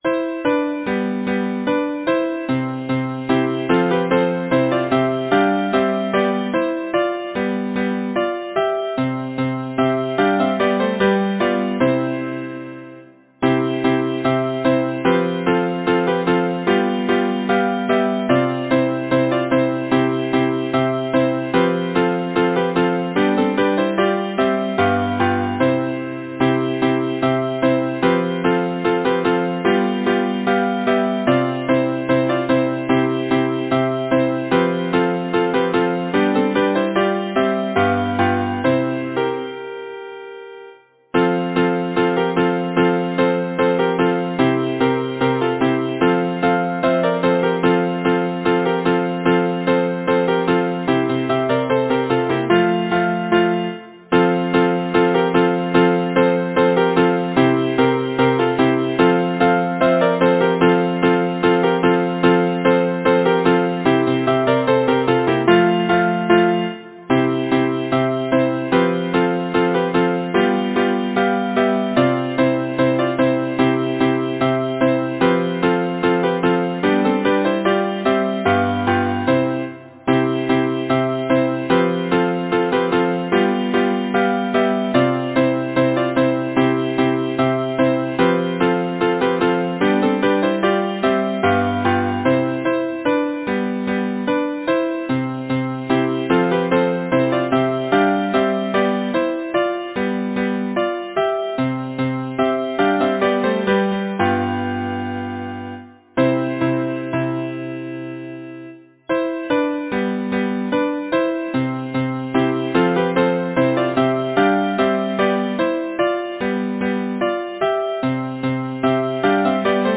Title: The Sleigh Ride Composer: Charles Clinton Case Lyricist: Number of voices: 4vv Voicing: SATB Genre: Secular, Partsong
Language: English Instruments: A cappella